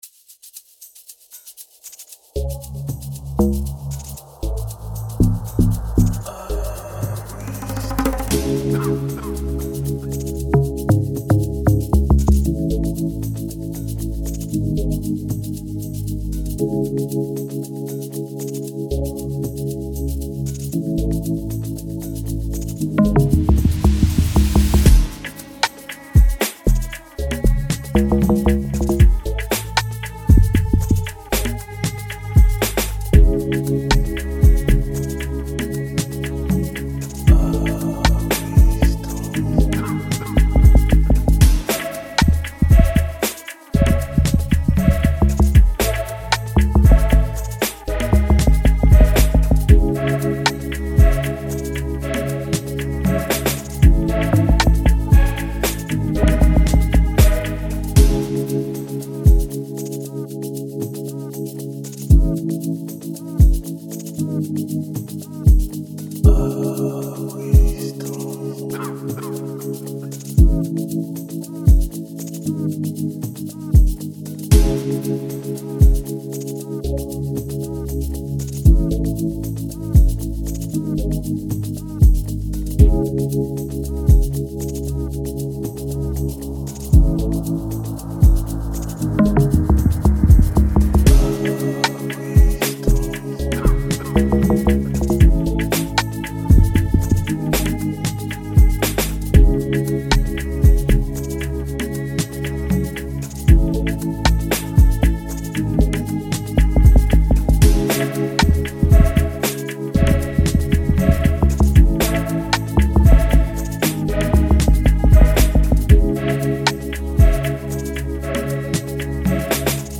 an Amapiano instrumental
With its distinctive Nigerian flavor